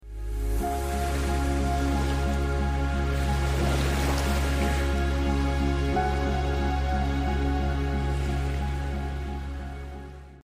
Escape to a magical night beach! Watch the waves🌊 dance under the Milky Way as coconut palms sway in the wind 🍃💫 You need more relaxation?